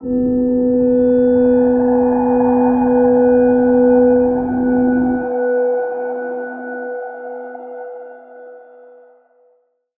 G_Crystal-B4-pp.wav